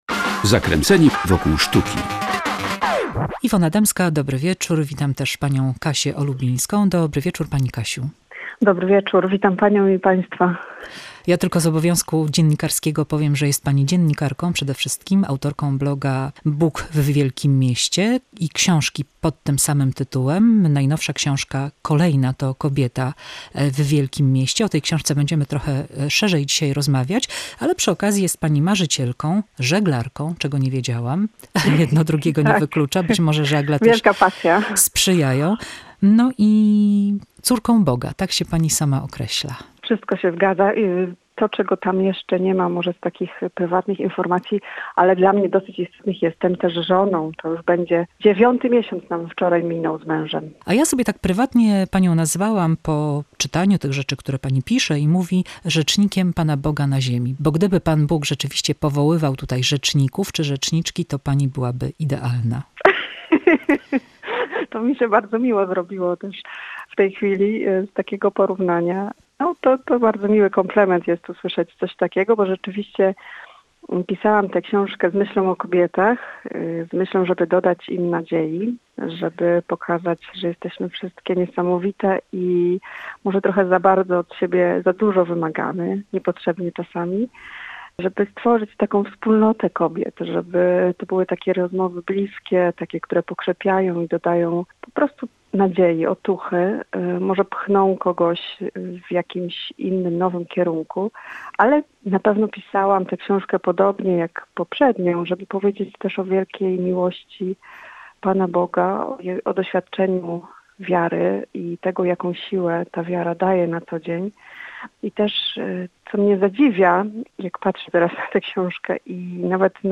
Jestem córką Boga – rozmowa z Katarzyną Olubińską